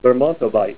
Say LERMONTOVITE